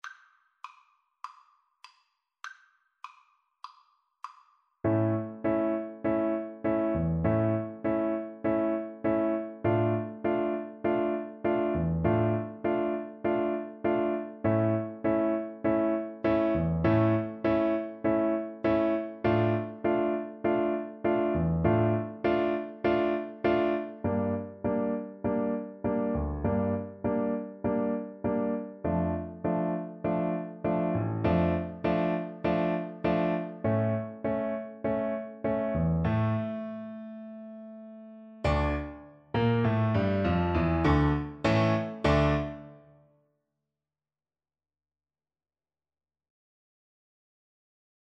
D minor (Sounding Pitch) (View more D minor Music for Oboe )
Tempo di Tango
4/4 (View more 4/4 Music)